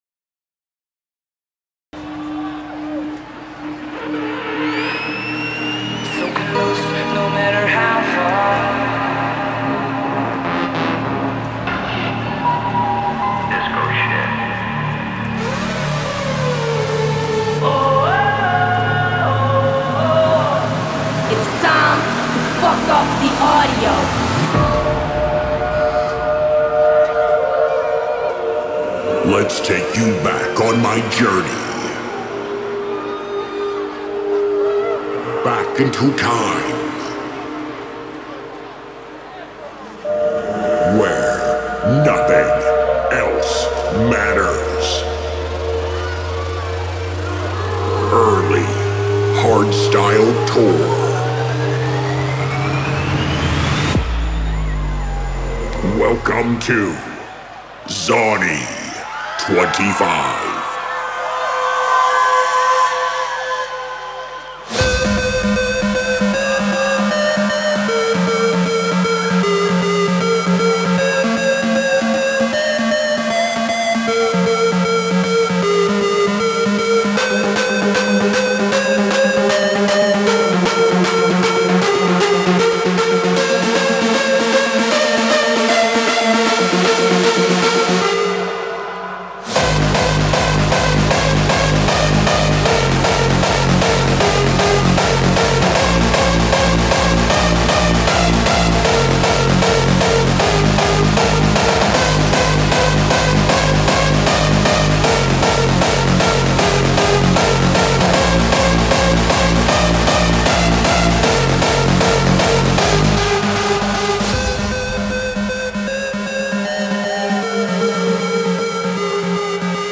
This liveset is embedded on this page from an open RSS feed.